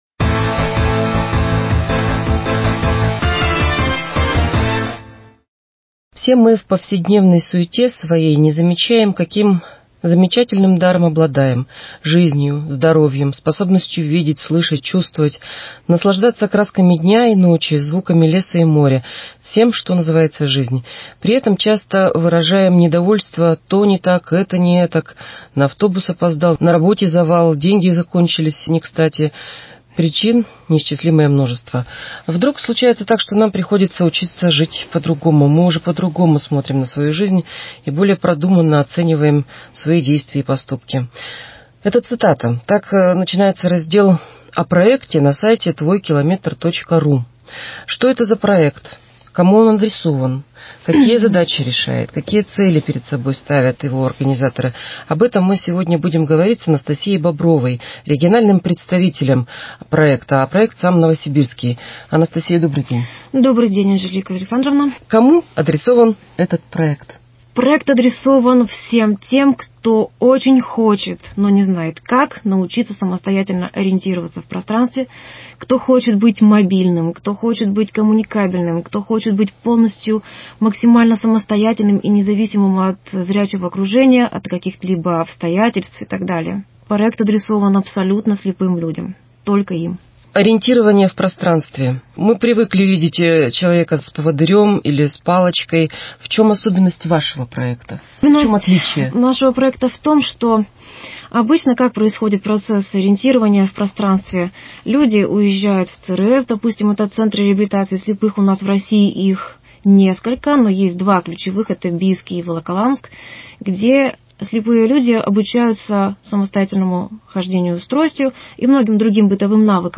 Актуальное интервью: «Твой километр» - проект по ориентированию слепых 18.06.2021